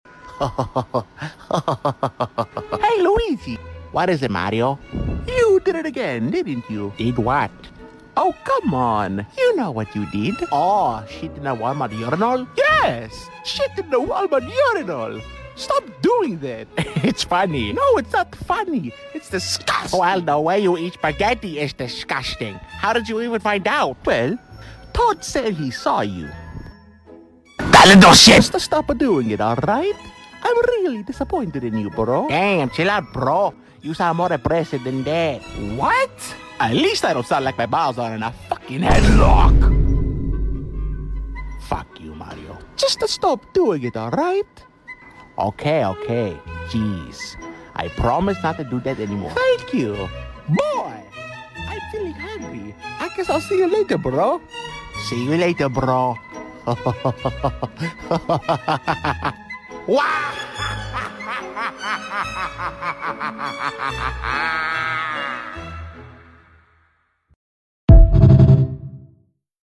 Luigi Has Problems Voiceover Sound Effects Free Download